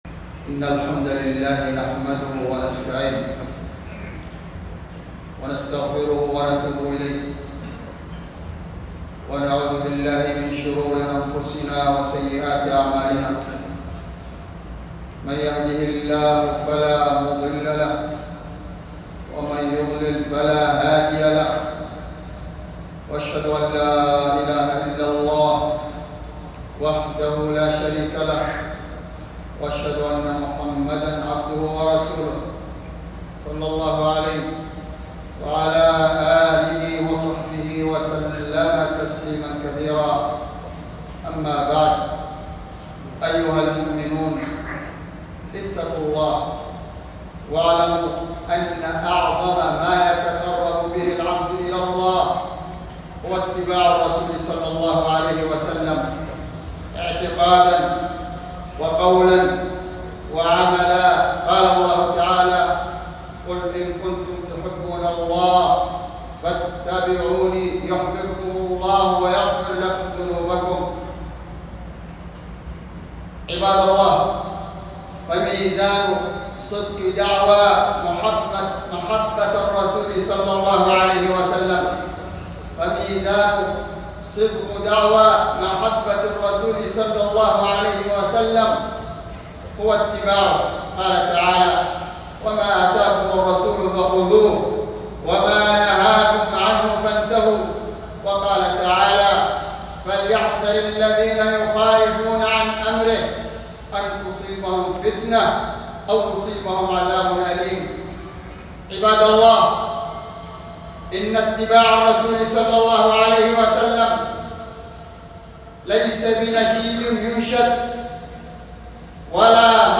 ينتمي إلى: الخطب